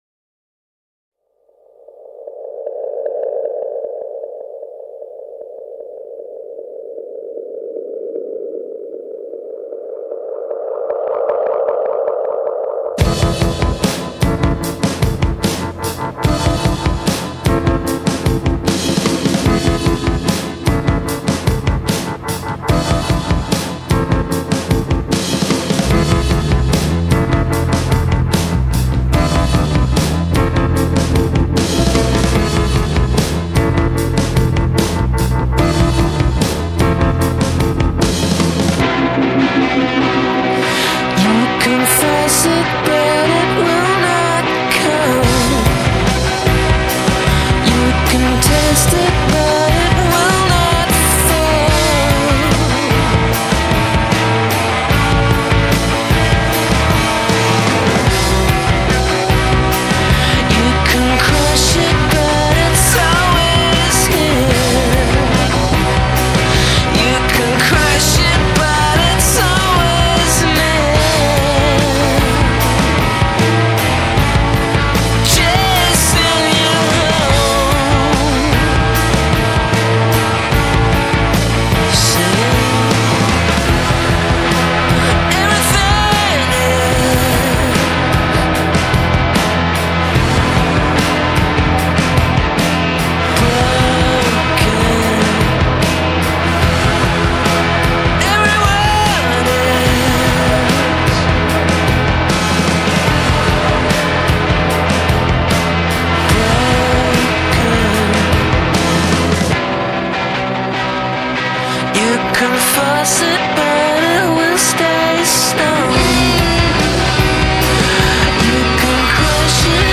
Alternative Rock, Post-Punk